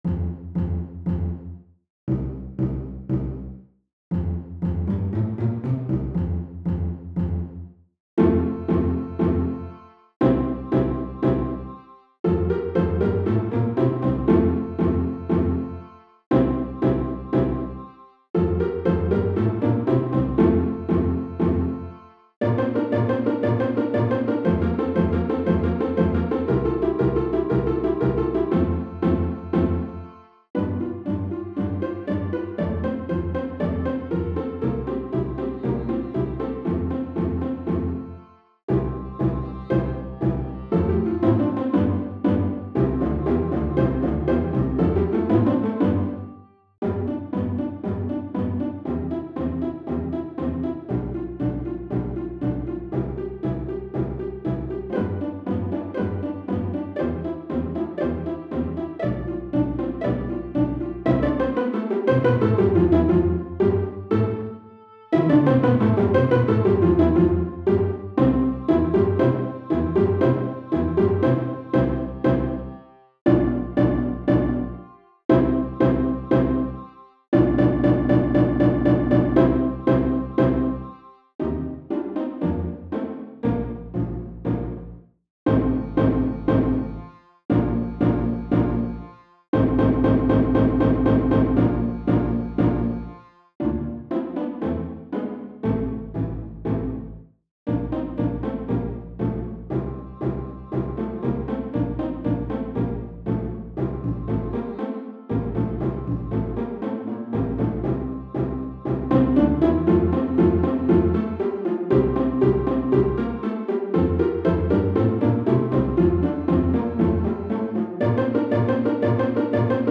장르세레나데, 디베르티멘토
호른 2, 바이올린 2, 비올라, 베이스로 편성되어 있다.
• 1악장: 소나타 형식의 알레그로, F장조
부자연스러운 7마디의 주제로 시작하며, 알베르티 베이스 등 밸런스를 잃은 프레이즈, 잘못된 음 진행, 금지된 병행 5도 진행, 기묘한 전조가 나타난다. 곳곳에서 울리는 호른도 웃음을 자아낸다.
제1악장 합성 버전